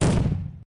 boom.ogg